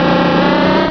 beedrill.wav